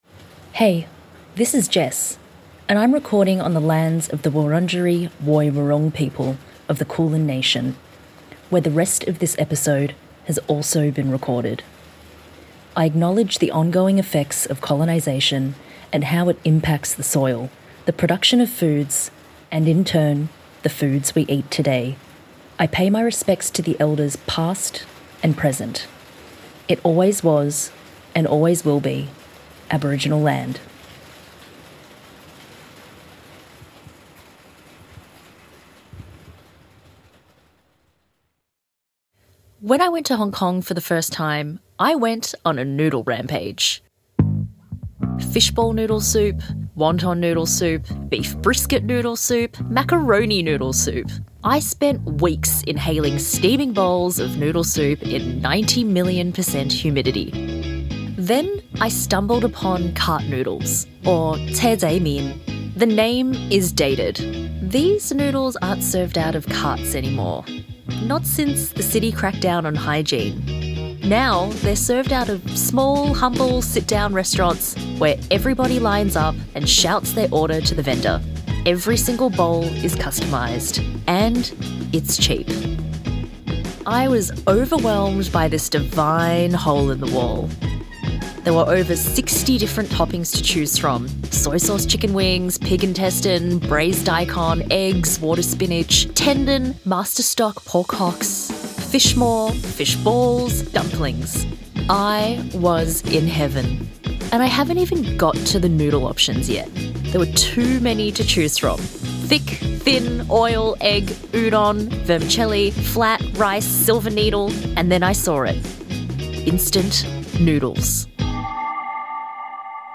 (atmospheric sounds of wind and rain)
(punchy bassline begins)